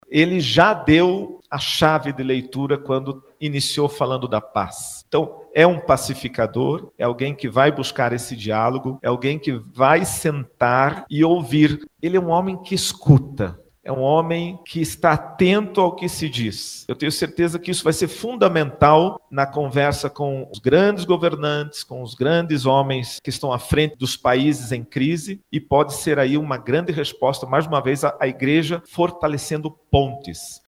A Conferência Nacional dos Bispos do Brasil (CNBB) realizou uma coletiva de imprensa na última quarta-feira, 08 de maio, para acolher o anúncio do Papa Leão XIV. Durante o evento, o Secretário Geral da CNBB, Dom Ricardo Hoepers, destacou o Papa Leão XIV como um símbolo de paz e conciliação.